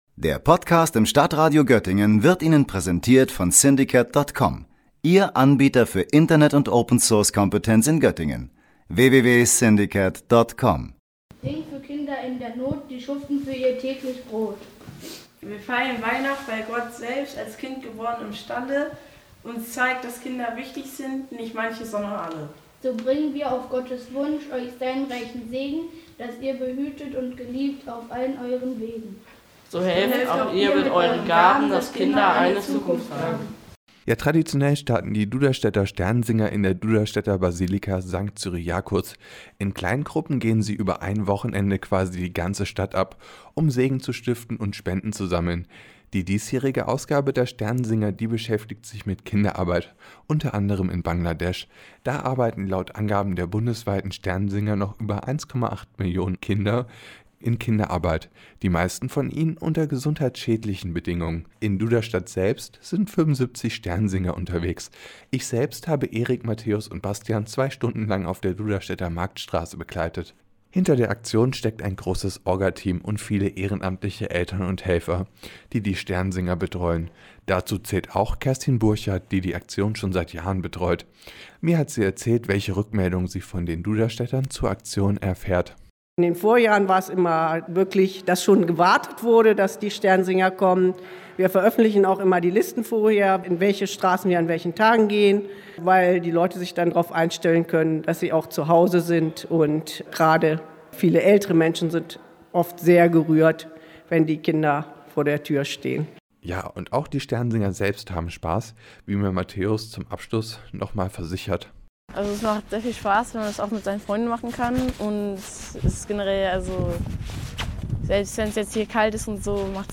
"Christus Mansionem Benedicat" – „Christus segne dieses Haus“: Mit diesem Segen ziehen viele Sternsinger momentan durch die Straßen. Heute hat die Aktion in Duderstadt begonnen.